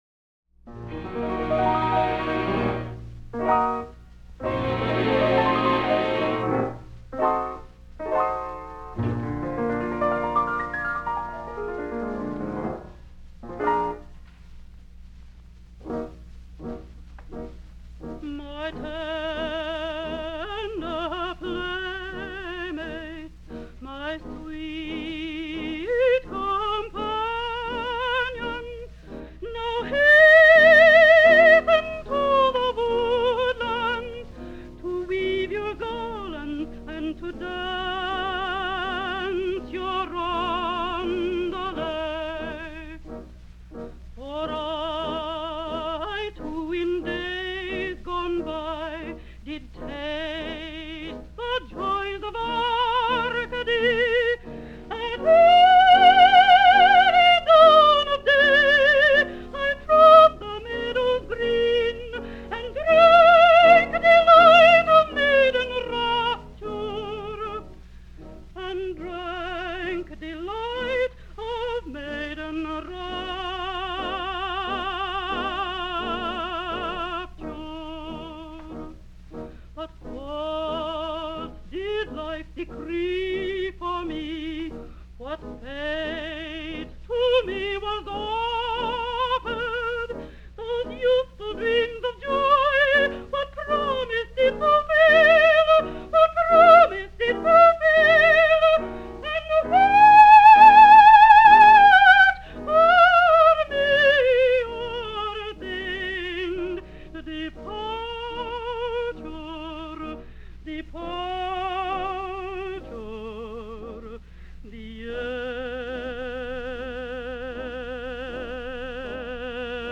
Глэдис Суортаут (25 декабря 1900 - 7 июля 1969) - американская оперная певица (меццо-сопрано) и актриса.